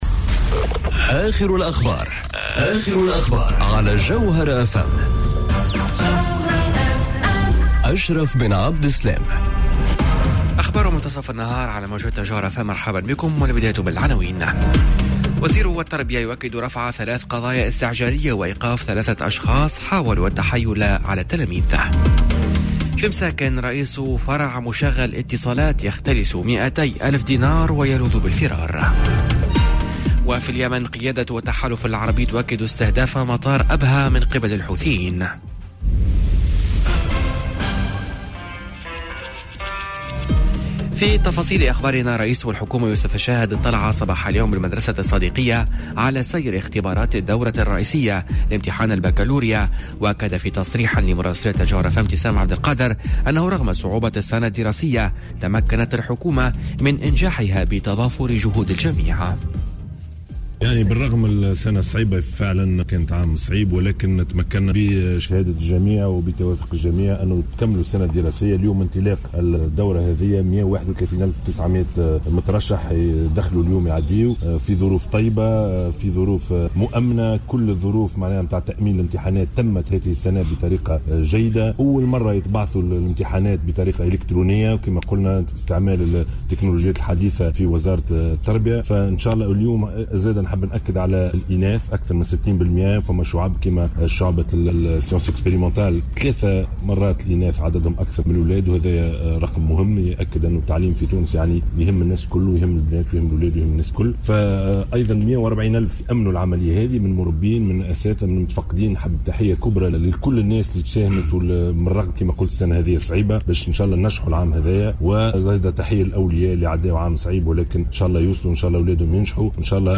نشرة أخبار منتصف النهار ليوم الإربعاء 12 جوان 2019